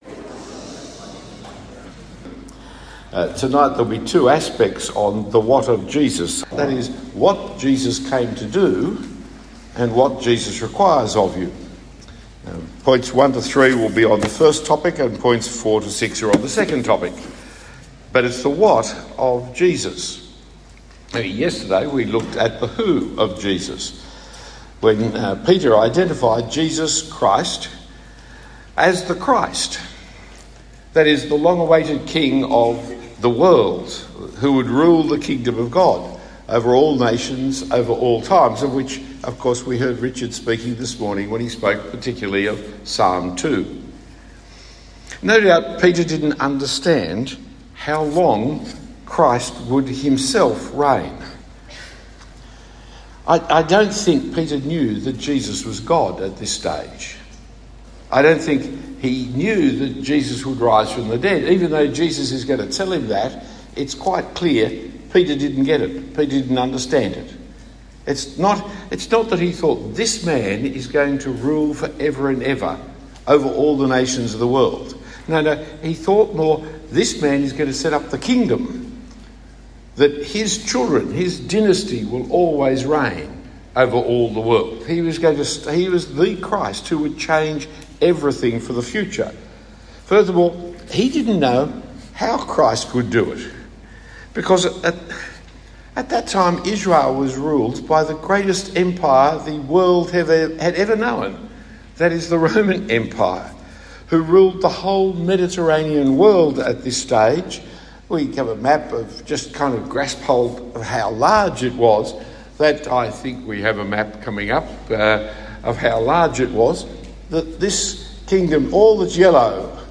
Talk 2 of 6 given at Launch 2025, a camp for school leavers keen to live for Jesus as they commence university life.